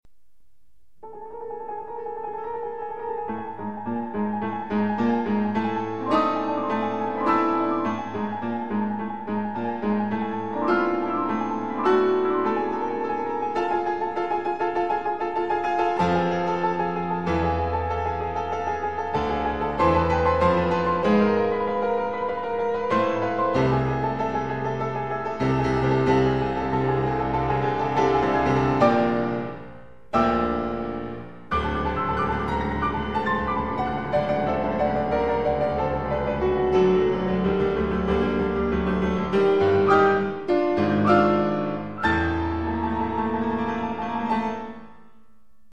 dans la version pour piano